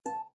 弦乐弹奏 - 声音 - 淘声网 - 免费音效素材资源|视频游戏配乐下载
在尤克里里琴上拔弦 12xxx 记录：NT1A Rode麦克风（冷凝器心形麦克风）使用的程序：Reaper v5.16 / x64编辑：Adobe Audition